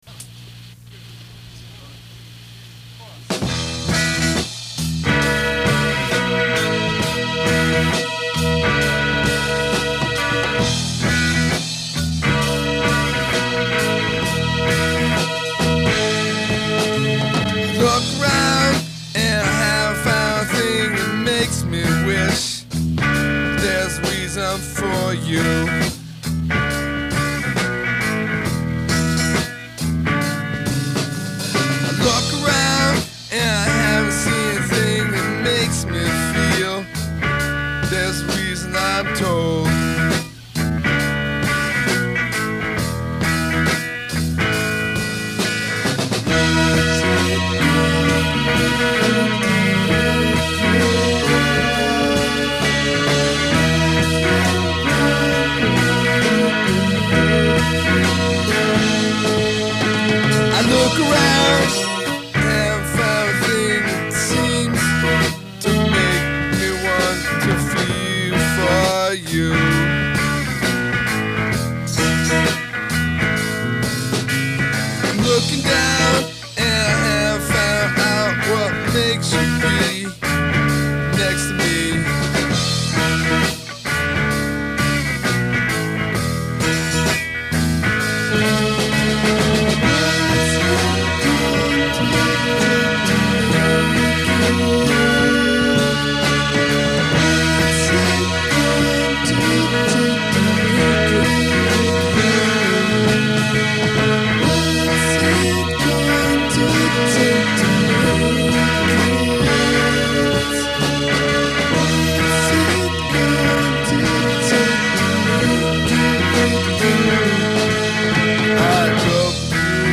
Kind of a slow reggae feel in three, jazz and punk mashed together. The melody is dissonant.  I think I slide down to an augmented 6th in the melody from flat seven. To my ears, just a chromatic dissonance to express despair.